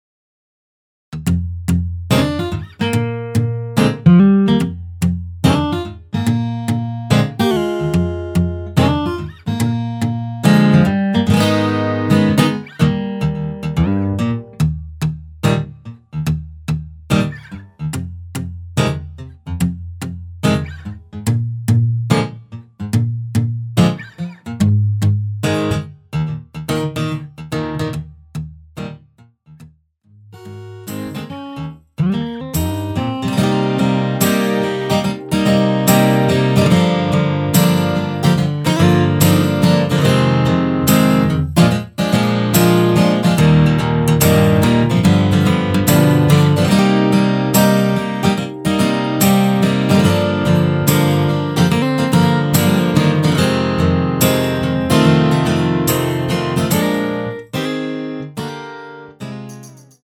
원키에서(-2)내린 MR입니다.
Eb
앞부분30초, 뒷부분30초씩 편집해서 올려 드리고 있습니다.
중간에 음이 끈어지고 다시 나오는 이유는